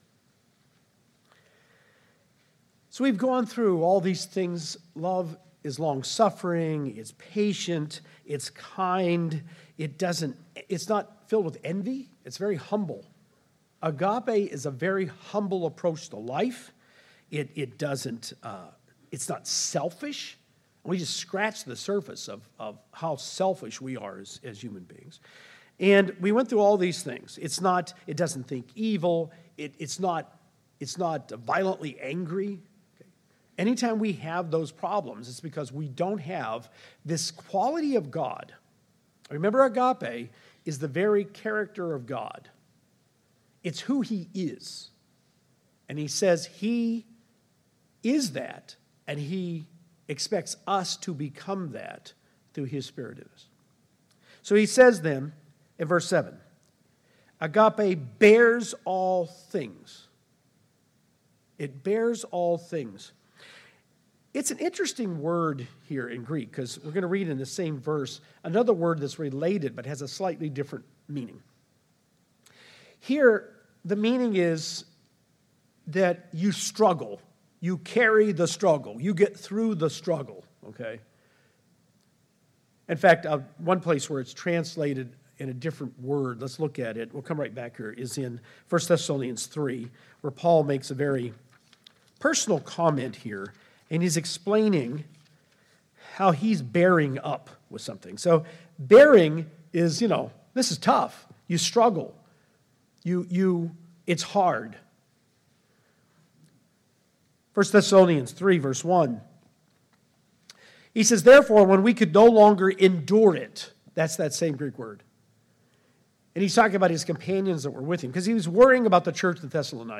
Final message in the agape series. Discussing 1 Corinthians 13:7.